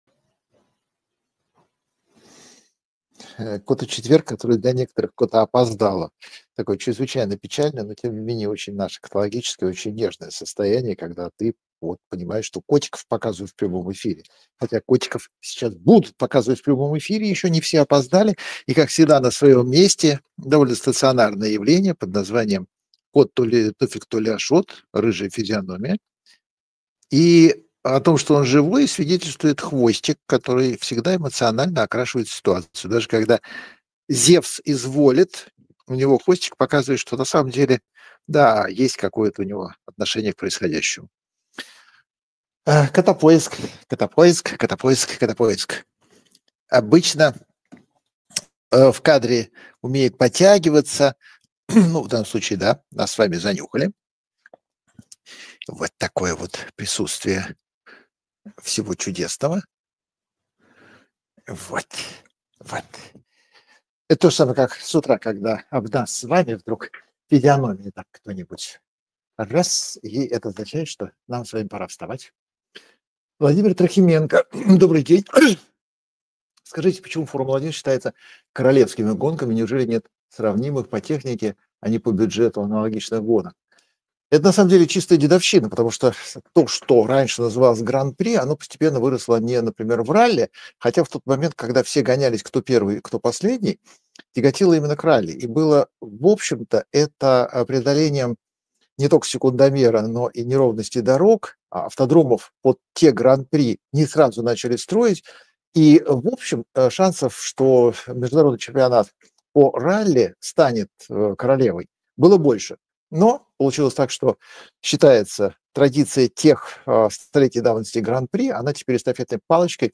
Прямая трансляция